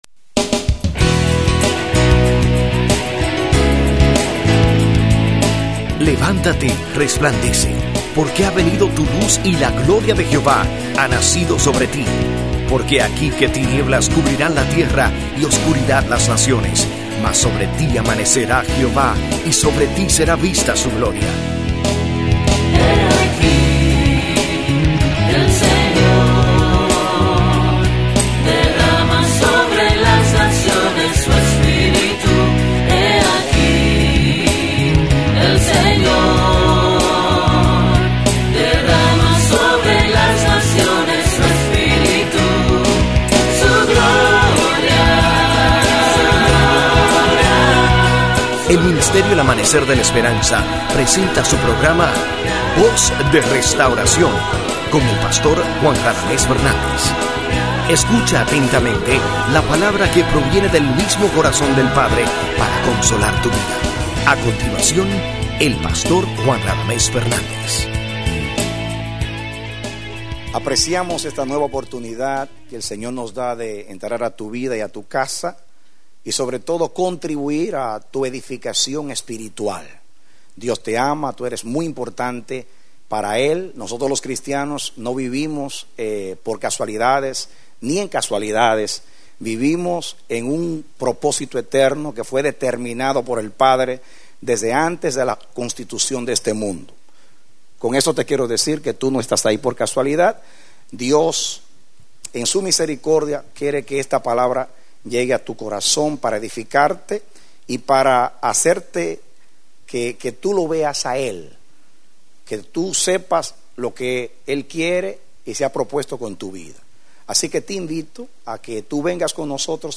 Predicado Diciembre 18, 2005